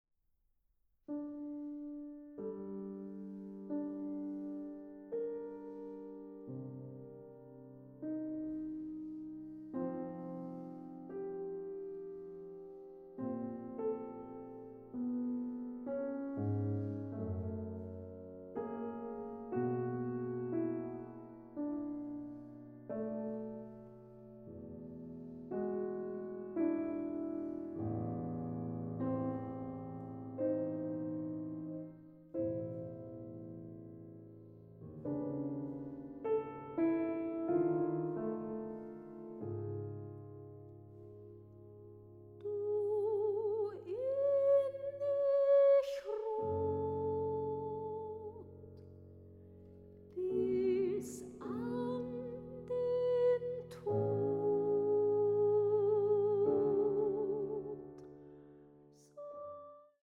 Sopran
Klavier
Aufnahme: Ackerscheune, Kulturstiftung Marienmünster, 2025